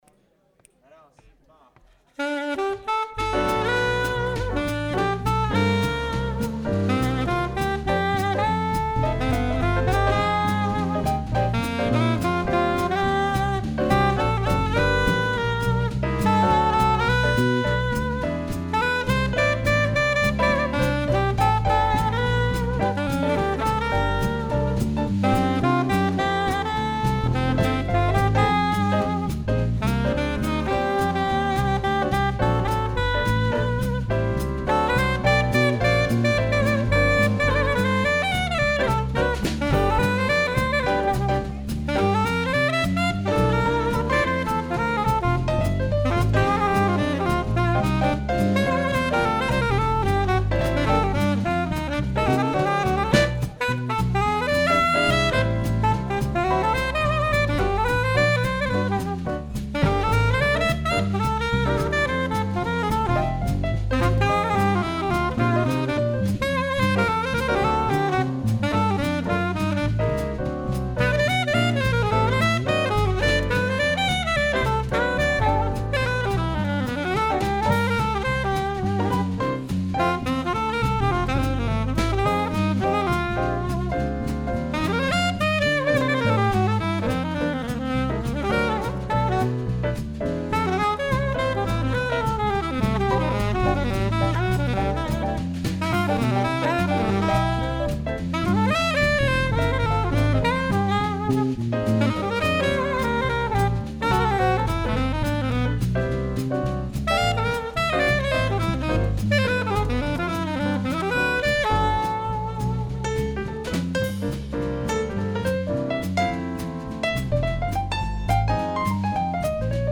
Naskytla se nám možnost zahrát si ve venkovních prostorech kavárny Podnebí.